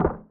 Win_Symbol_Fall.mp3